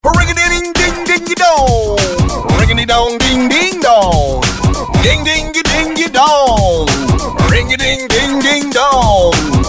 забавные
веселые
смешные